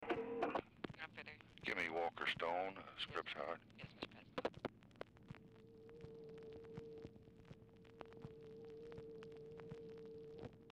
TELEPHONE OPERATOR
Oval Office or unknown location
Telephone conversation
Dictation belt